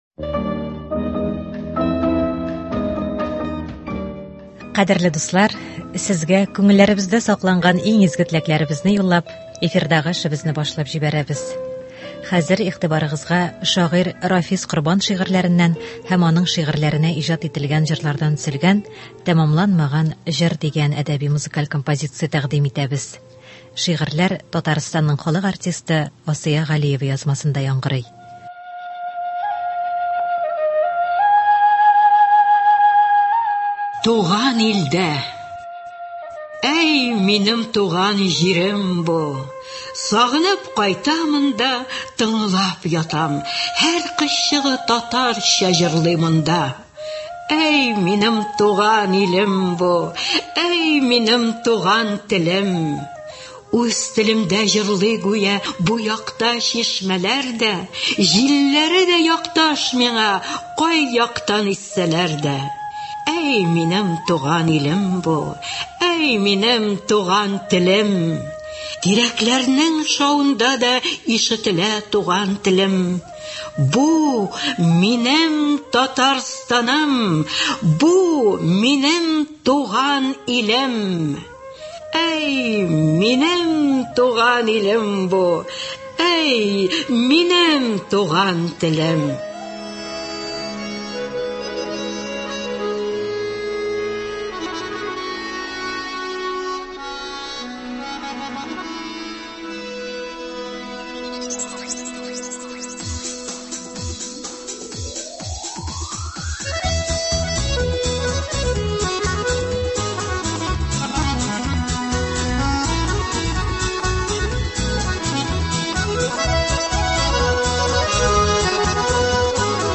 Рафис Корбан әсәрләреннән әдәби-музыкаль композиция (04.05.24)